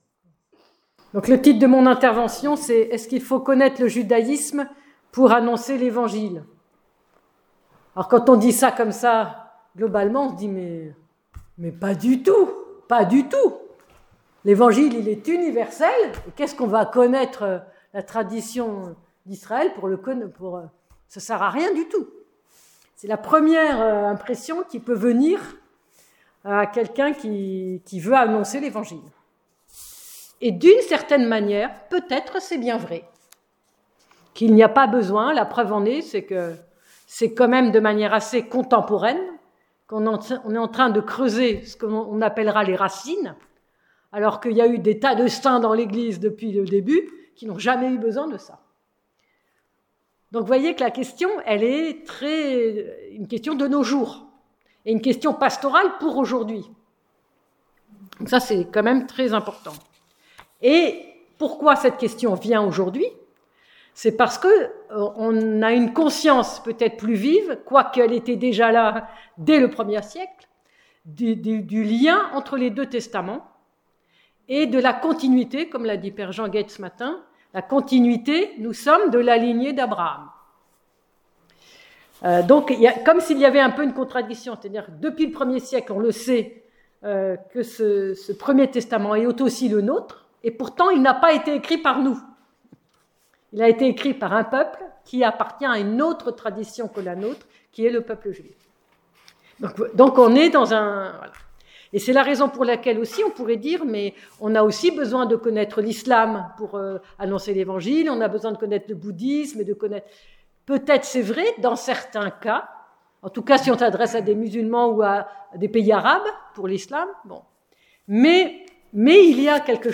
Cette intervention qui s'est déroulée à la fin de l'université de rentrée de l'Institut Saint-Serge, a proposé une réflexion sur l'importance du milieu entièrement juif dans lequel se déroulent les récits évangéliques. Peut on vraiment comprendre l'évangile et par conséquent l'annoncer d'une part en ne connaissant qu'une faible partie de l'Ancien Testament, sans avoir aucune idée de la façon dont il est lu par la synagogue et d'autre part sans connaître le milieu socio-culturel juif du premier siècle, ainsi que ses coutumes et ses croyances ?